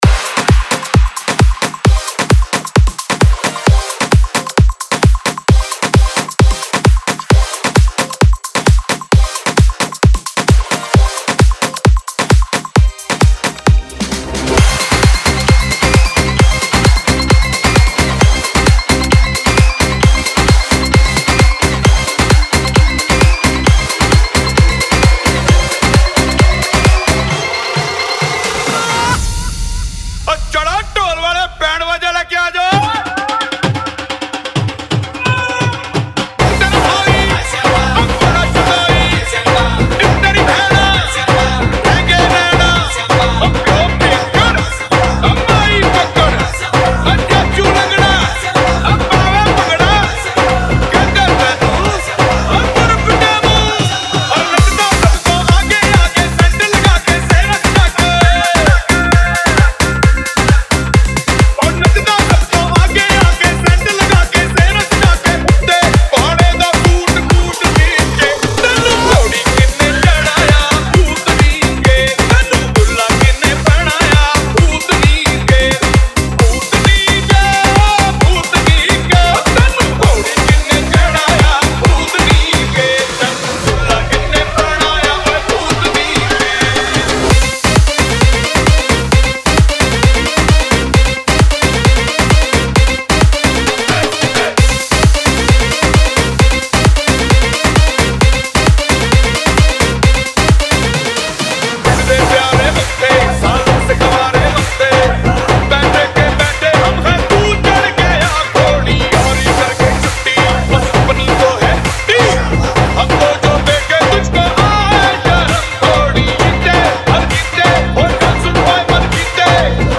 Bollywood Single Remixes